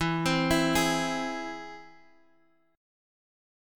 E+ chord